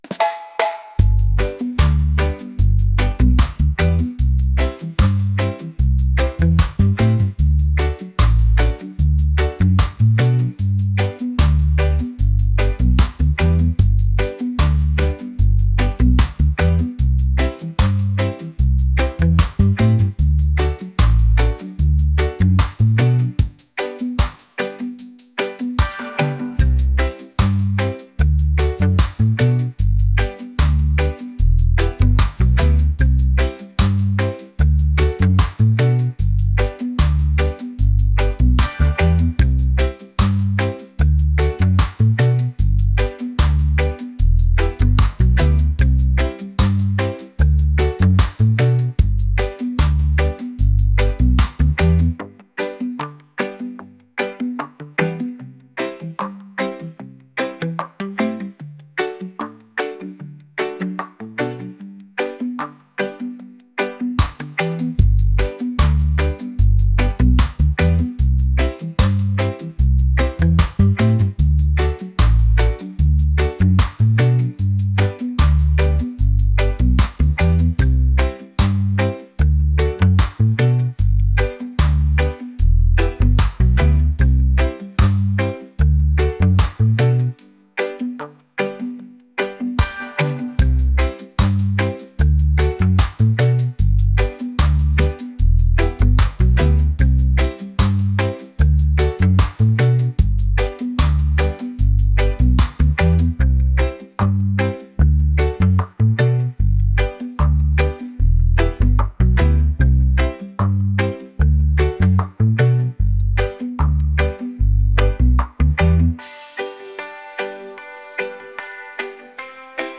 island | reggae | laid-back